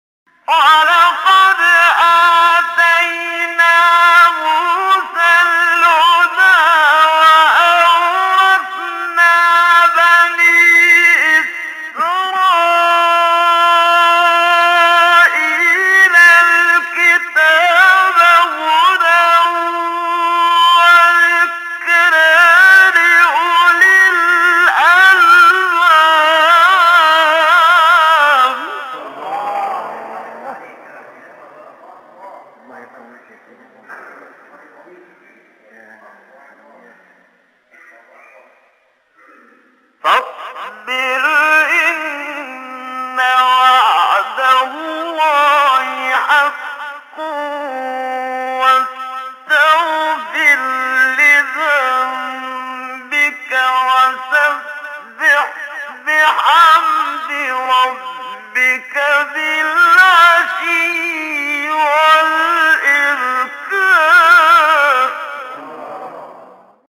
سوره : غافر آیه: 53-55 استاد : محمد صدیق منشاوی مقام : رست قبلی بعدی